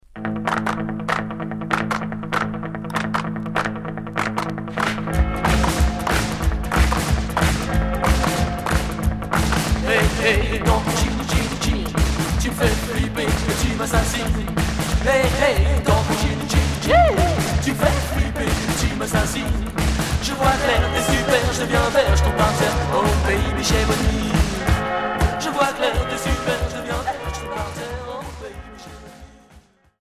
Rockabilly Unique 45t